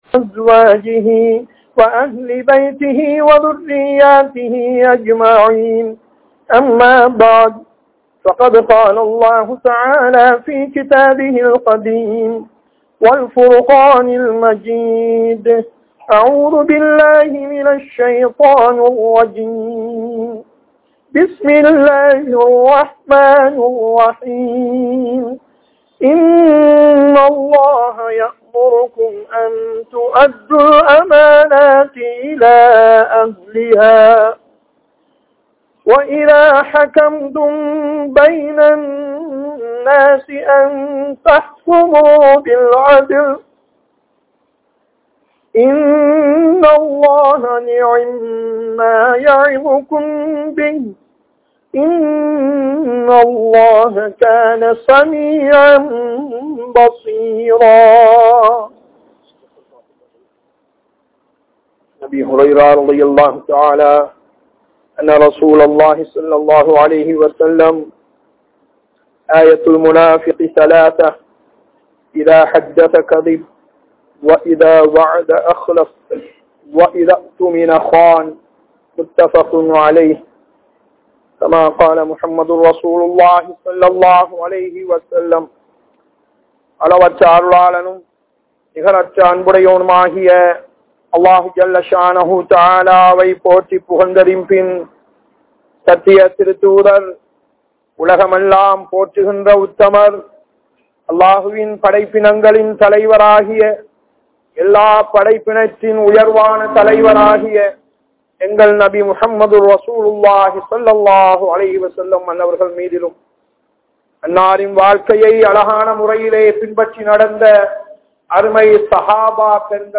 Amaanitham (அமானிதம்) | Audio Bayans | All Ceylon Muslim Youth Community | Addalaichenai